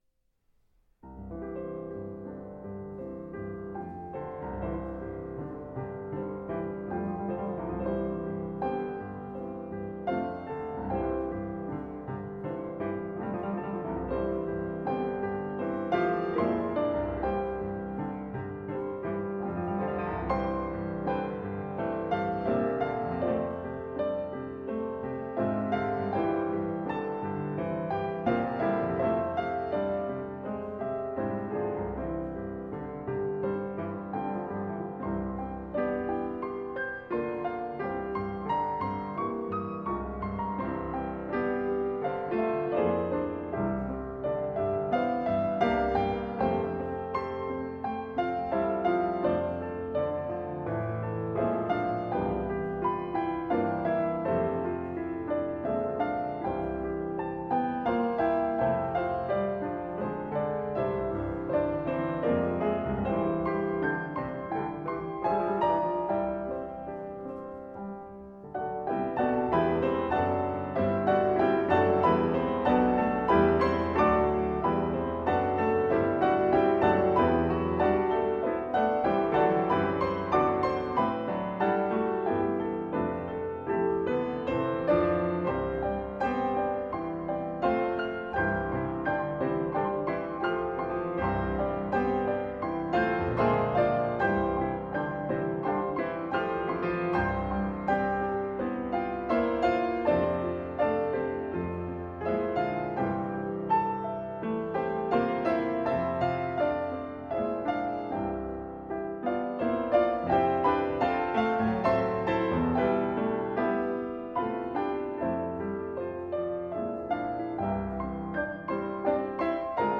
Pianists